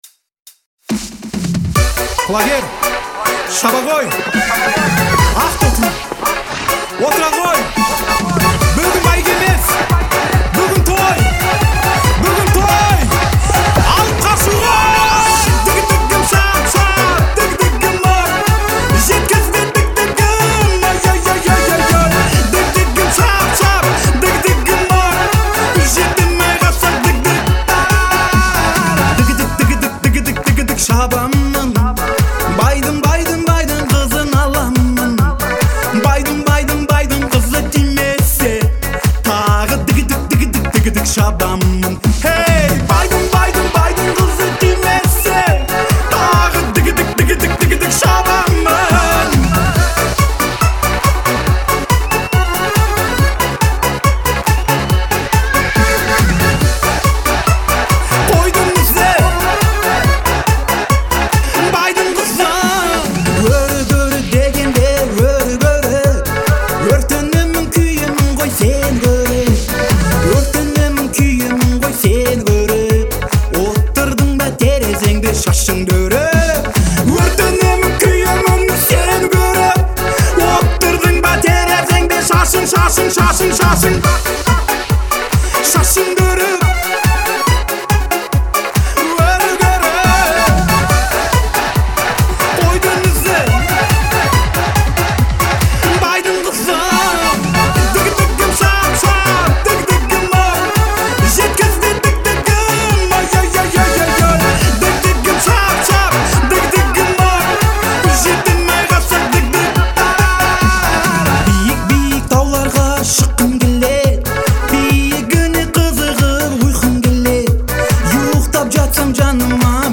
это яркая и мелодичная песня в жанре казахского поп-фолка
Отличительной чертой исполнения является глубокий вокал